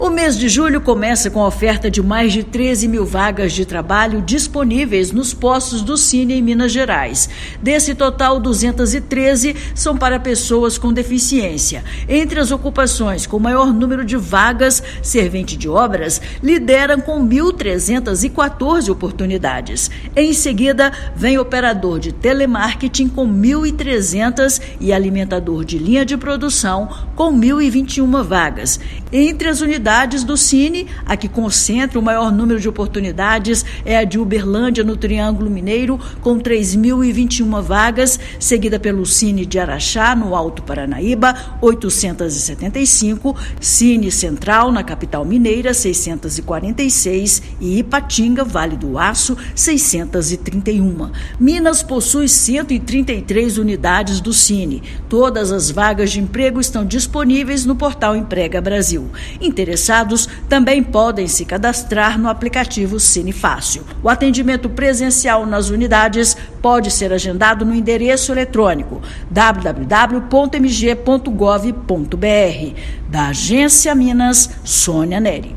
Servente de obras e operador de telemarketing lideram em número de oportunidades. Ouça matéria de rádio.